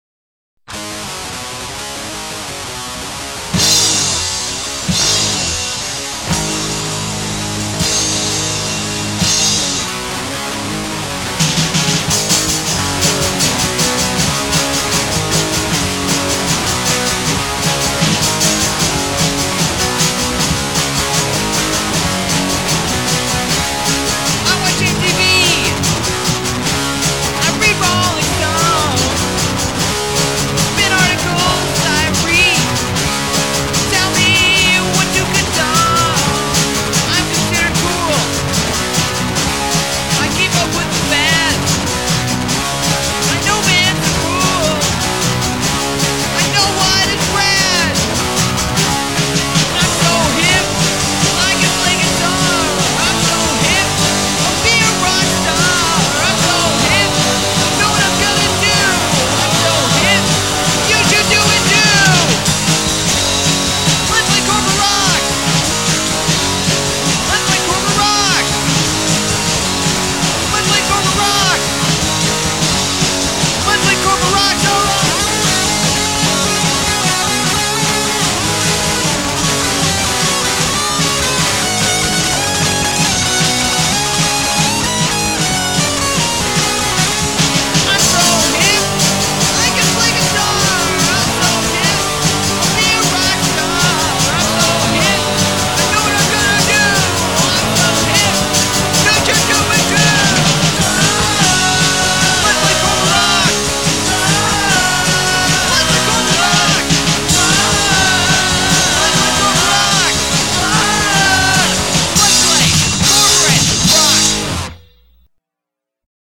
The first full length from a budding pseudo-punk band.
We still only had one mic for the drums.